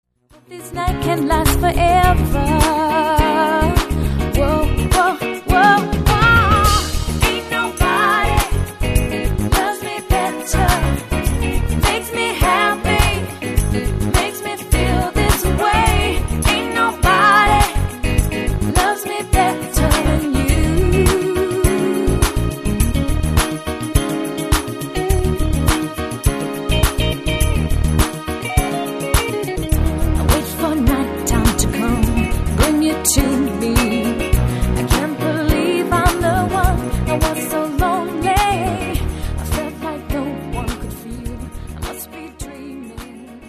Guitar Vocals - Female Vocals - Keyboard - Bass - Drums - 2nd Female Vocal
delivering an energetic, fresh and interactive performance.